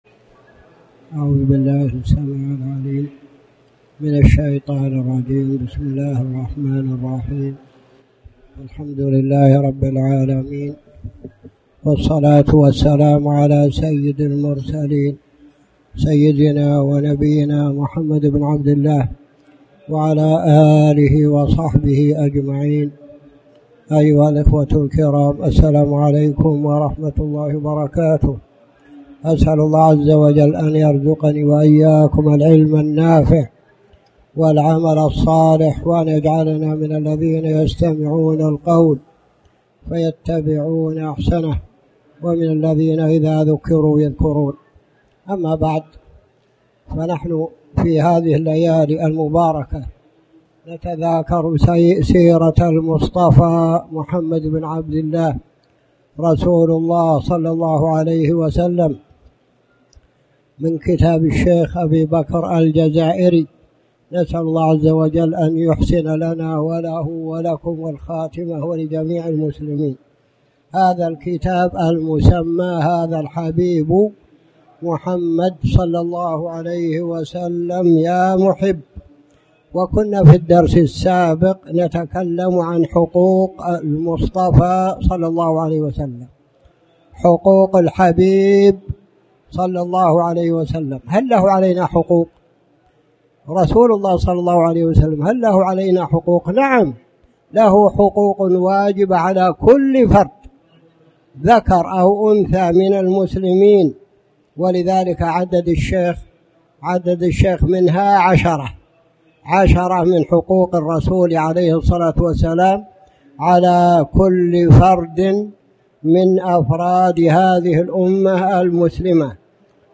تاريخ النشر ١٧ محرم ١٤٣٩ هـ المكان: المسجد الحرام الشيخ